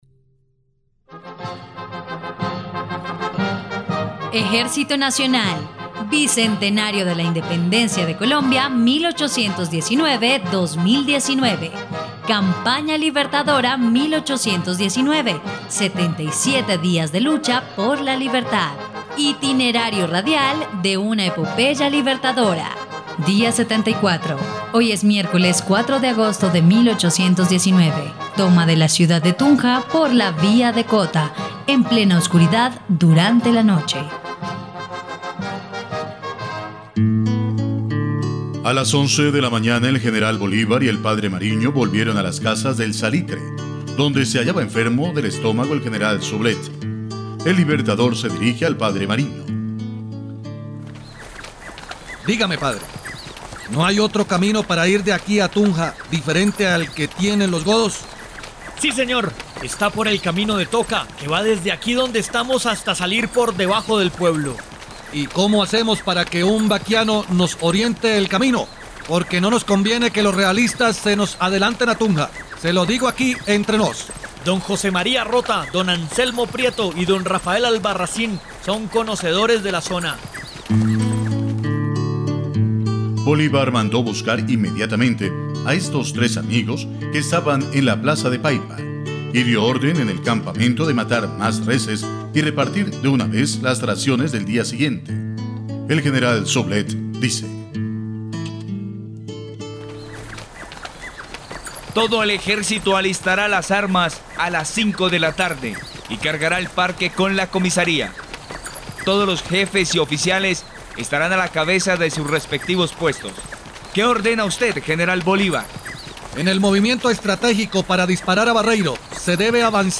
dia_74_radionovela_campana_libertadora.mp3